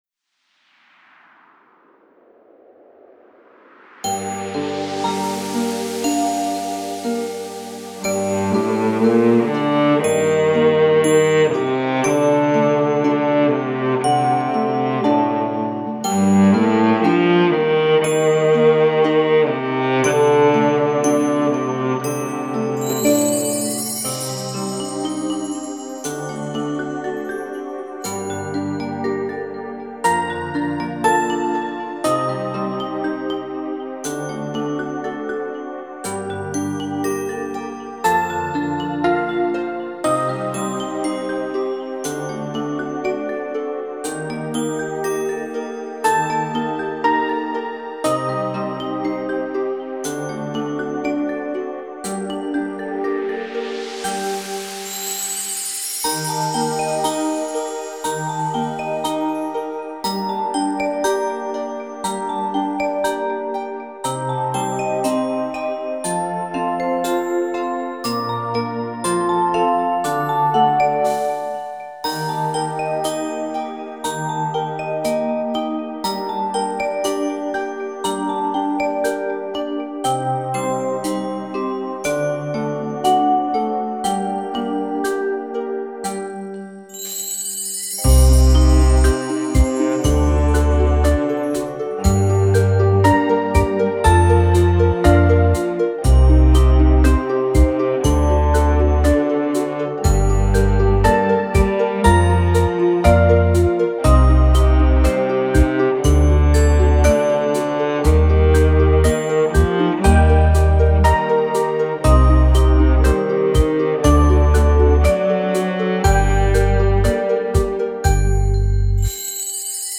Фонограмма